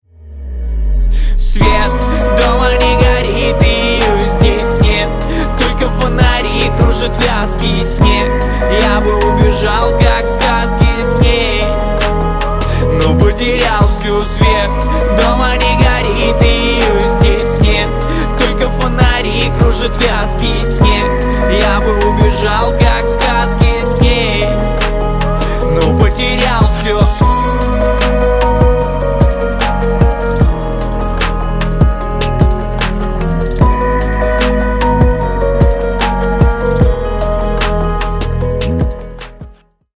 Поп Музыка # Рэп и Хип Хоп